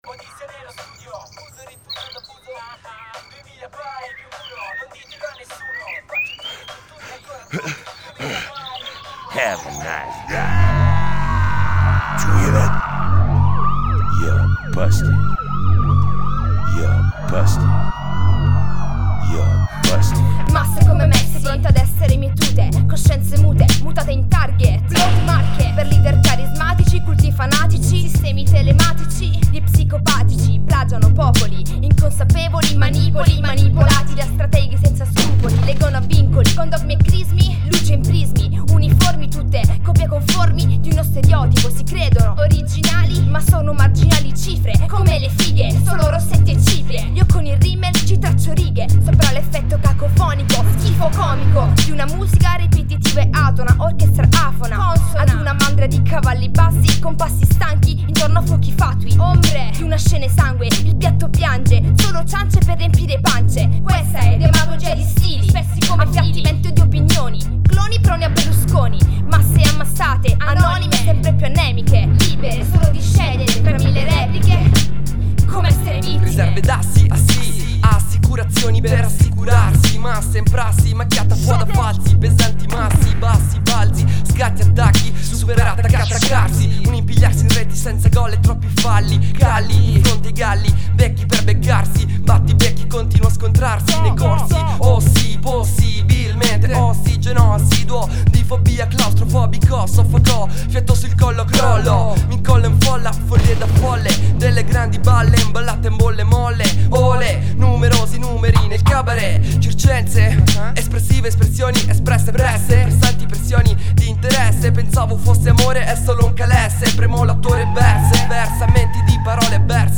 Hip Hop italiano.